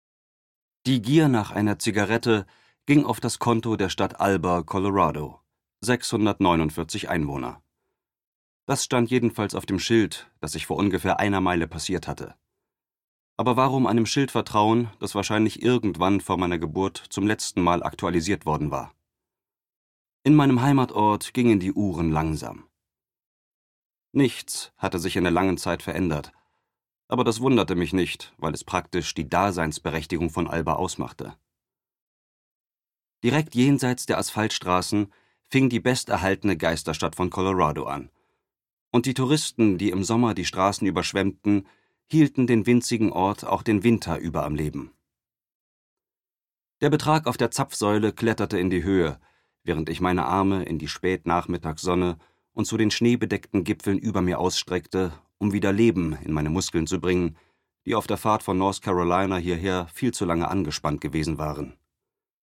sehr variabel, dunkel, sonor, souverän
Mittel minus (25-45)
Audiobook (Hörbuch)